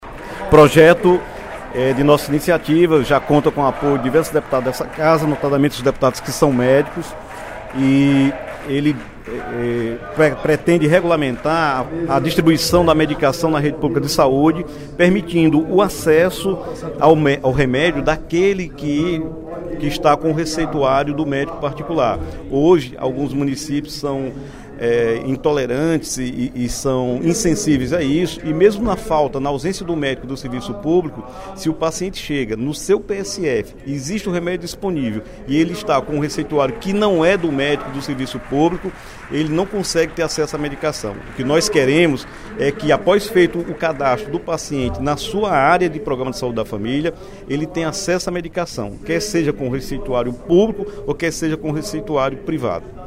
O deputado Dr. Santana (PT) lembrou, durante o primeiro expediente da sessão plenária desta terça-feira (12/07), projeto de lei de sua autoria, conhecido como “lei do receituário médico”, que tramita na Assembleia Legislativa desde o ano passado. A matéria regulamenta o despacho da receita médica, possibilitando que o paciente receba os medicamentos por meio do Programa Saúde da Família (PSF), portando receita oriunda de médicos do Sistema Único de Saúde (SUS) ou da rede privada.